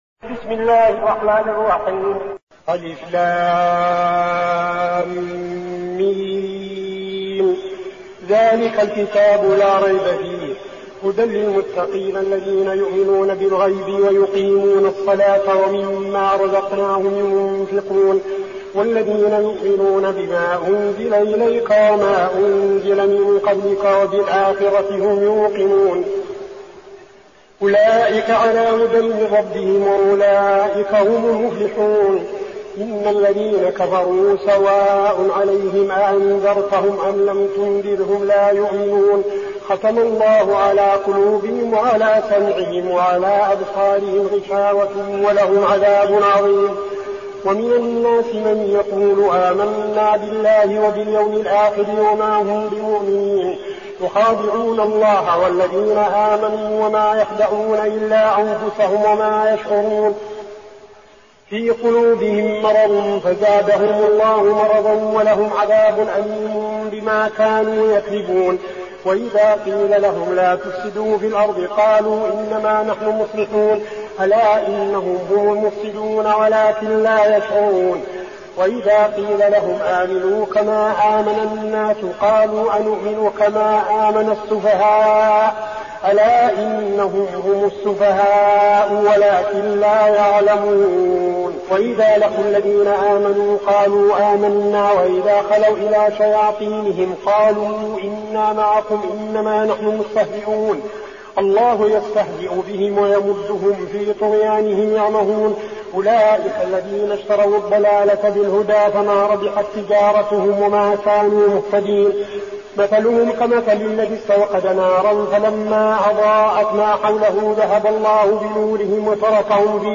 المكان: المسجد النبوي الشيخ: فضيلة الشيخ عبدالعزيز بن صالح فضيلة الشيخ عبدالعزيز بن صالح البقرة